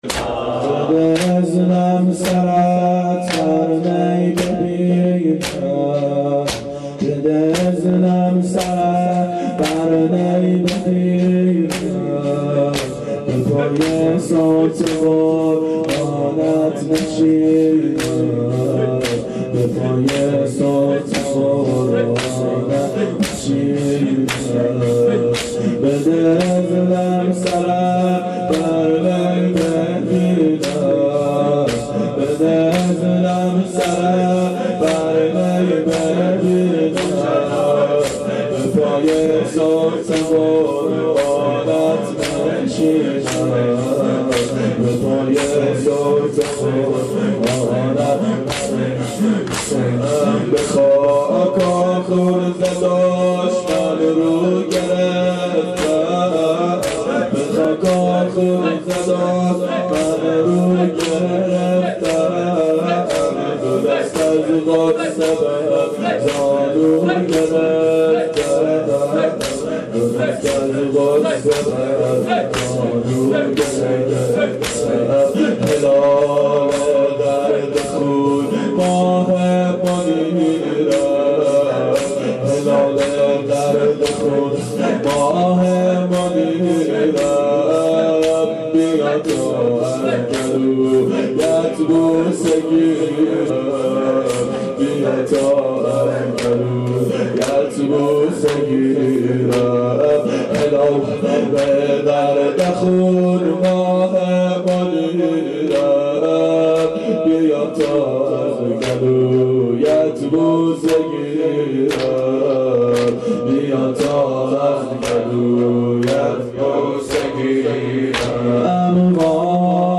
شب عاشورا 1389 هیئت عاشقان اباالفضل علیه السلام